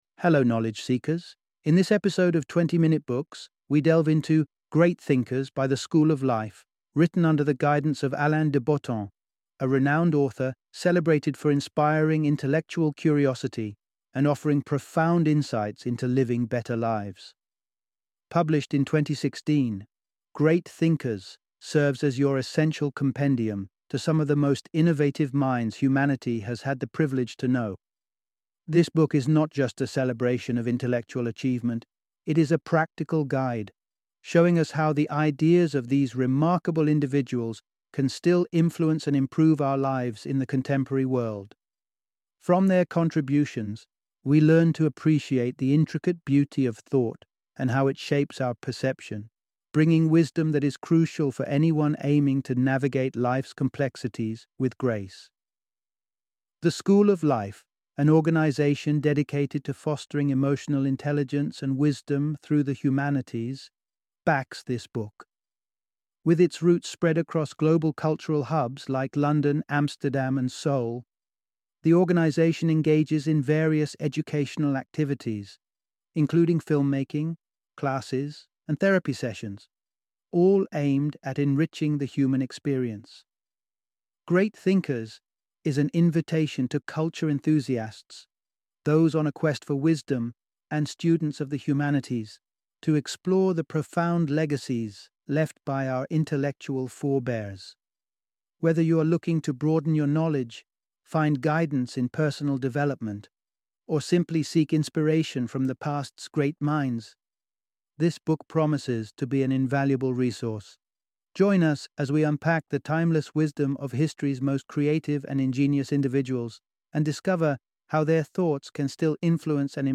Great Thinkers - Audiobook Summary